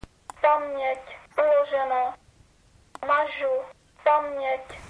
Mluvící kalkulačka
Kalkulačka nemá displej, všechny funkce a stisk tlačítek jsou ozvučeny.